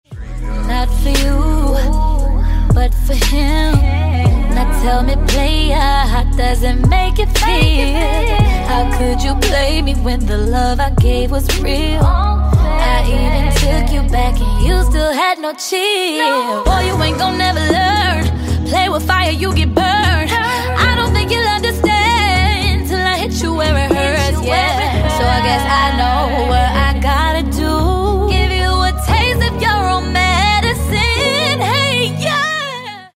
R&B Soul